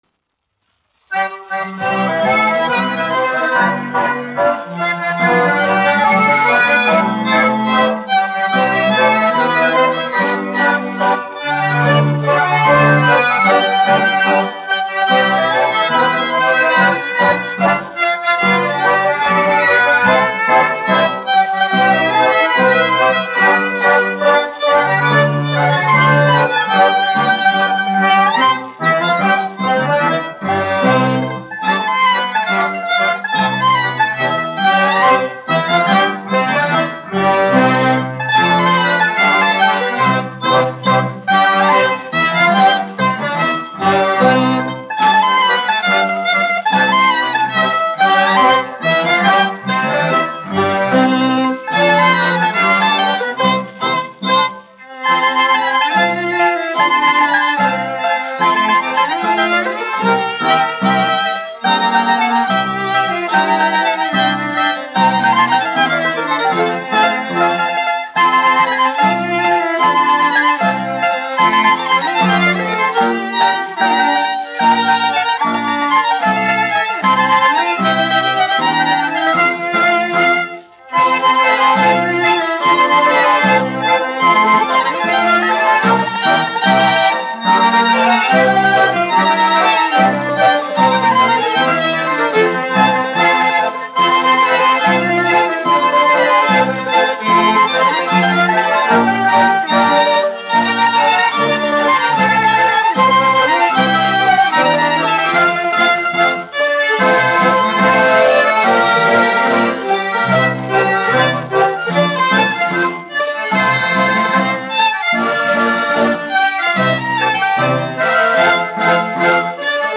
1 skpl. : analogs, 78 apgr/min, mono ; 25 cm
Sarīkojumu dejas